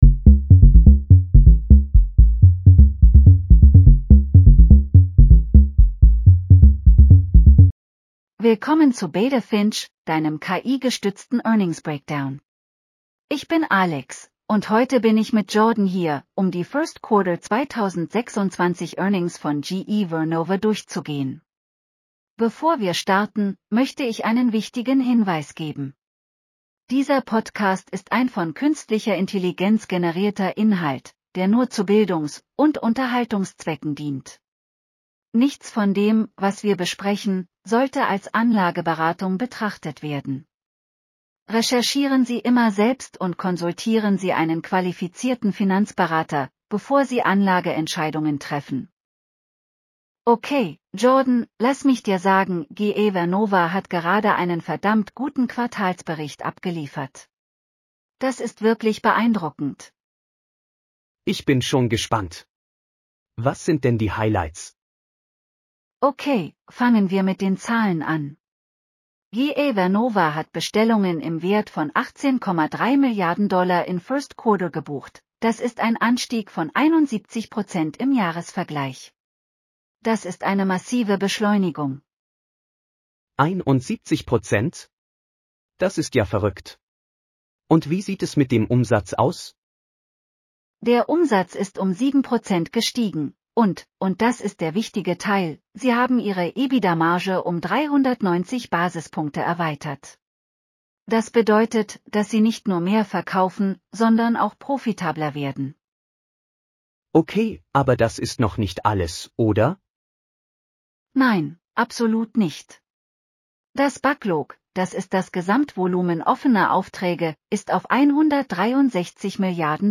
GE Vernova Q1 2026 earnings call breakdown.